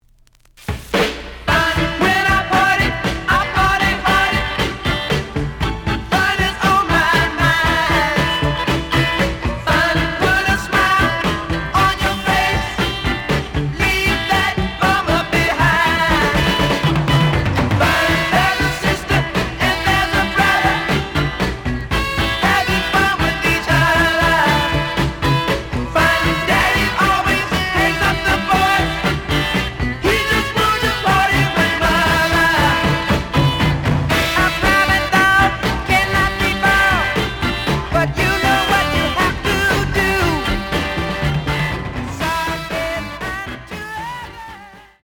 The audio sample is recorded from the actual item.
●Genre: Funk, 60's Funk
Some noise on parts of both sides.)